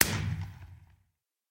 blast_far.mp3